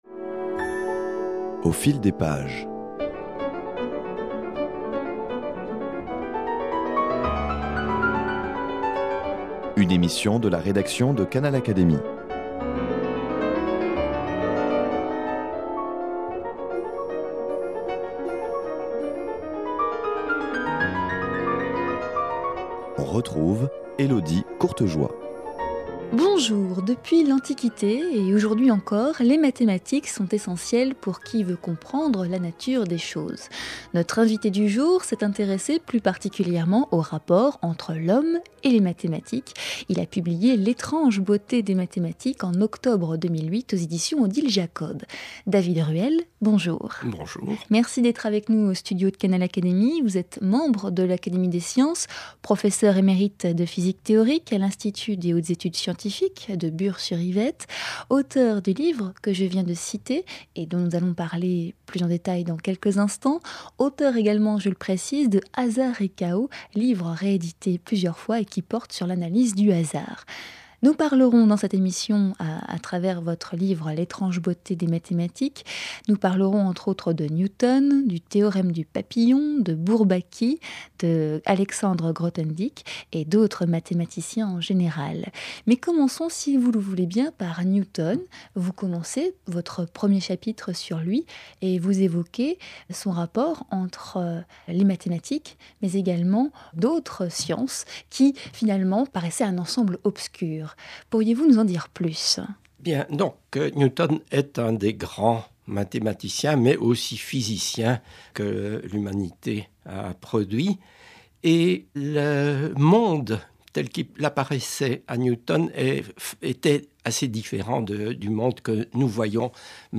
Dans L’étrange beauté des mathématiques, David Ruelle s’est intéressé au rapport, parfois passionnel, que l’homme entretient avec les mathématiques depuis Newton. Tour d’horizon en compagnie de l’auteur, physicien et membre de l'Académie des sciences.